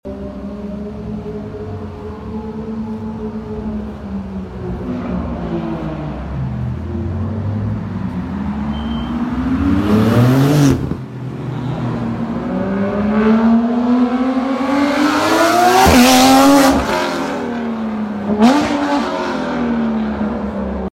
TITANIUM SUPERLIGHT RACE PIPE (NON SILENCED)